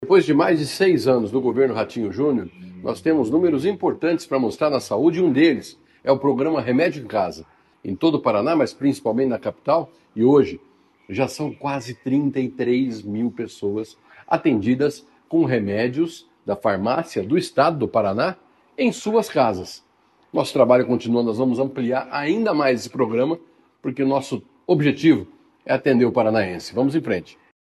Sonora do secretário da Saúde, Beto Preto, sobre o programa Remédio em Casa